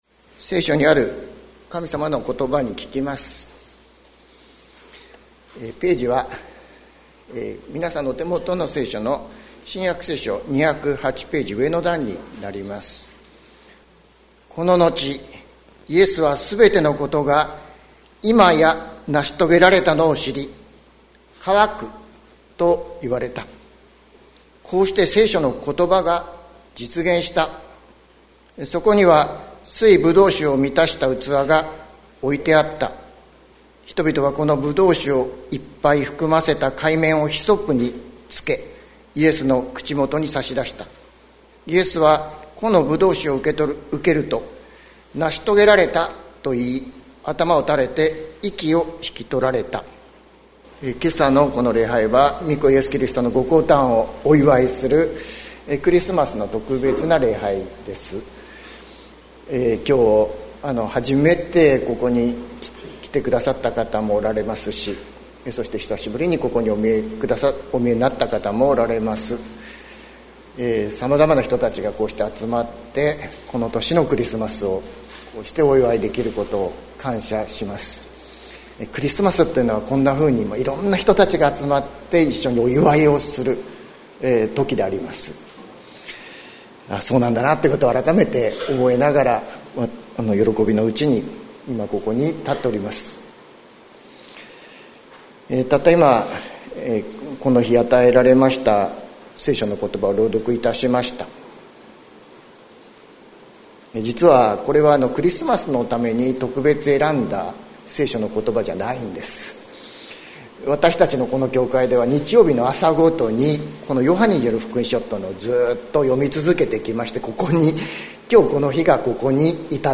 2023年12月25日朝の礼拝「成し遂げられた」関キリスト教会
説教アーカイブ。